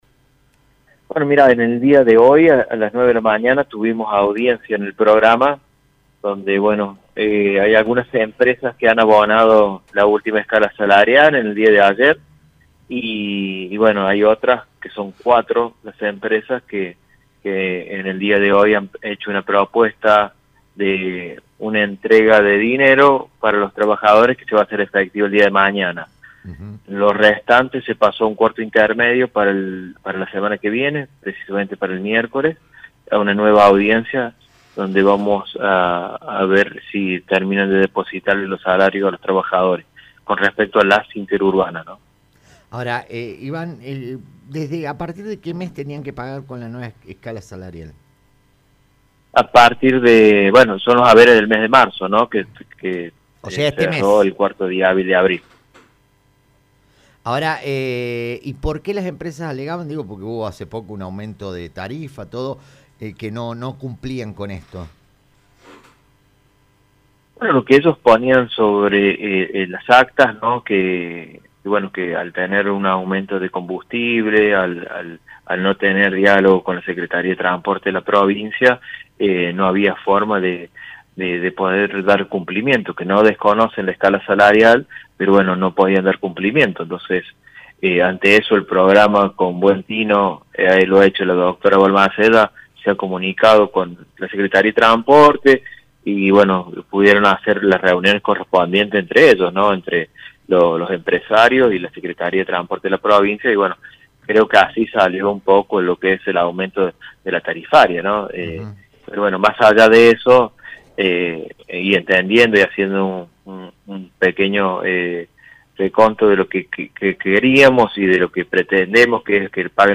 En una entrevista exclusiva con El Show de la Tarde